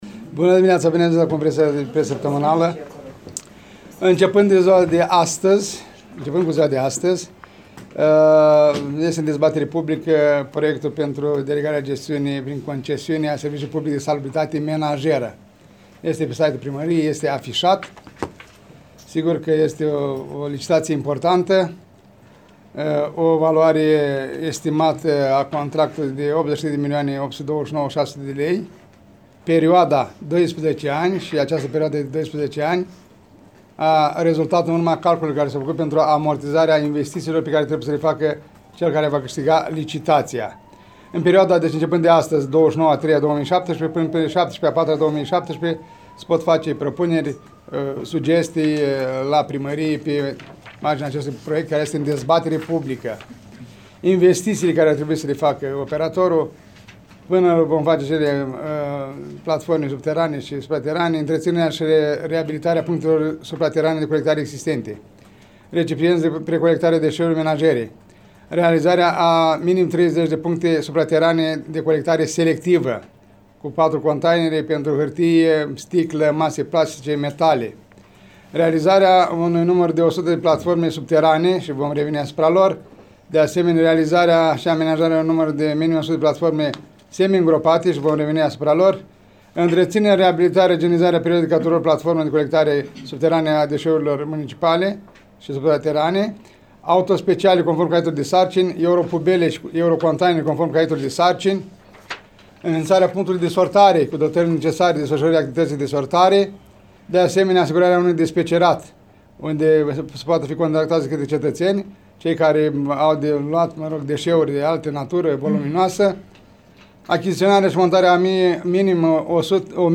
Primarul Ion Lungu a precizat care sunt investiţiile pe care ar trebui să le facă viitorul operator: